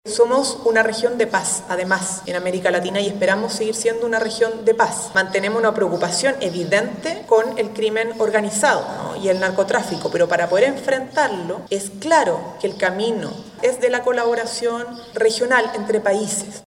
Consultada por La Radio, la ministra vocera de Gobierno, Camila Vallejo, afirmó que miran con atención y preocupación la presencia militar de Estados Unidos, lo que, a su juicio, no contribuye a un clima de paz en la región.